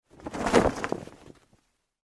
Звуки флага
5. Маленький флажок трепещет